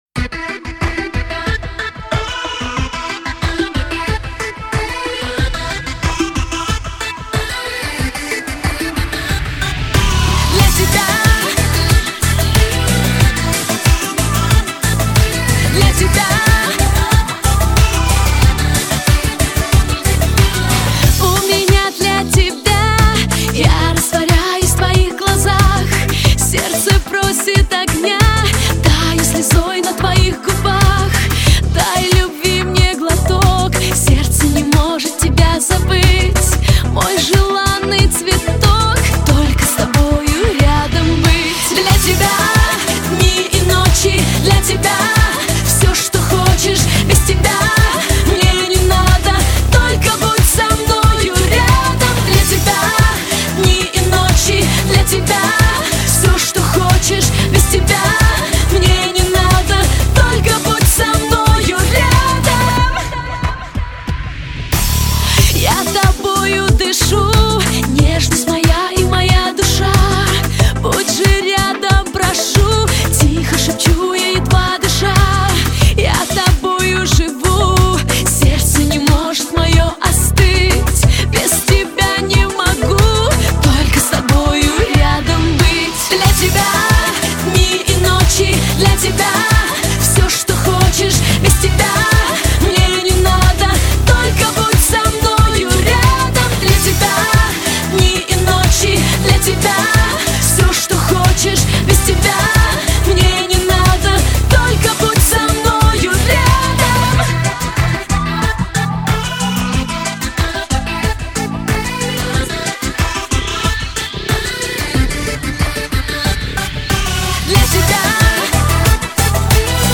Жанр: Только качественная POP музыка